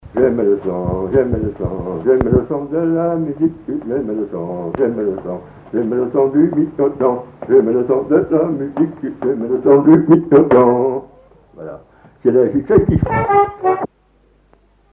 Commequiers
branle
Couplets à danser
violoneux, violon
Répertoire sur accordéon diatonique
Pièce musicale inédite